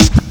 Index of /90_sSampleCDs/Best Service ProSamples vol.15 - Dance Drums [AKAI] 1CD/Partition A/SD 121-180